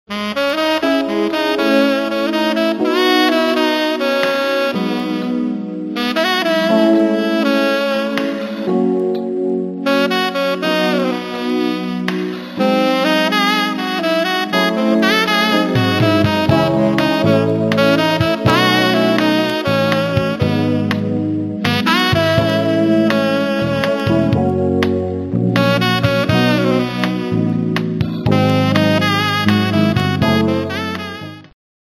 красивые
без слов
инструментальные
медленные
Красивая мелодия на саксофоне